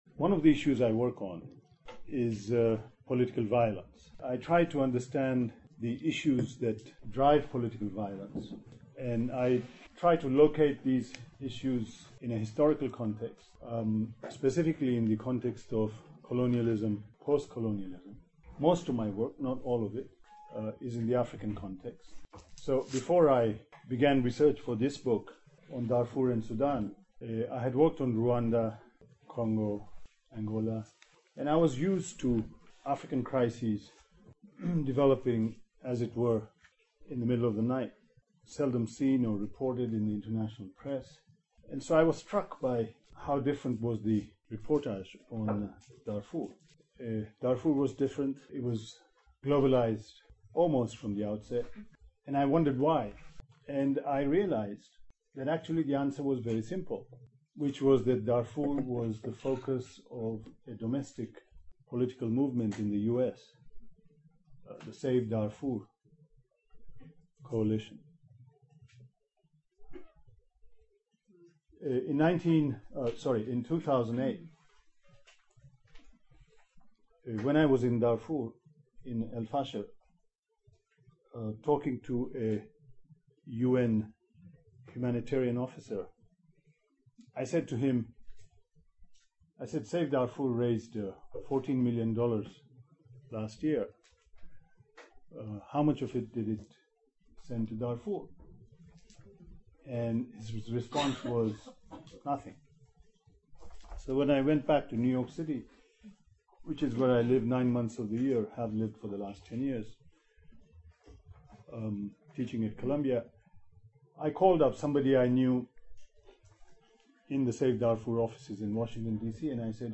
mamdani-soas-3-june-2009-edit.mp3